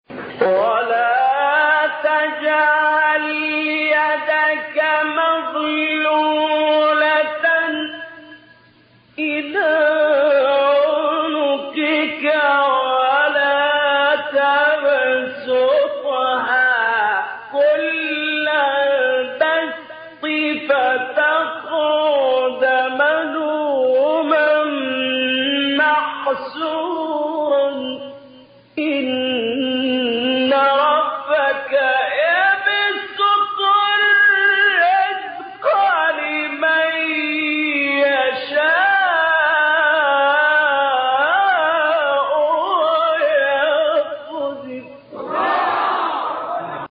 گروه شبکه اجتماعی: مقاطعی صوتی با صوت محمد اللیثی را که در مقام‌های مختلف اجرا شده است، می‌شنوید.
به گزارش خبرگزاری بین المللی قرآن(ایکنا) پنج فراز صوتی از سوره مبارکه اسراء با صوت محمد اللیثی، قاری برجسته مصری در کانال تلگرامی قاریان مصری منتشر شده است.
مقام صبا